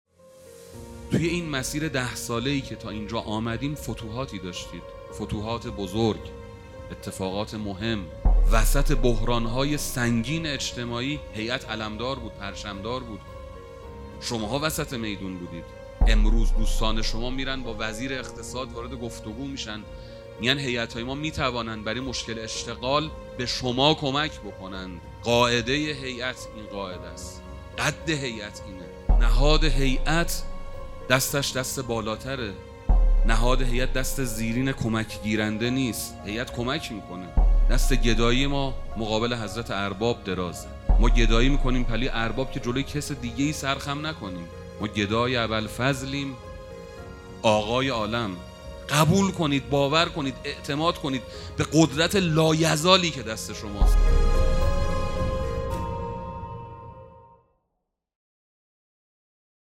پانزدهمین همایش هیأت‌های محوری و برگزیده کشور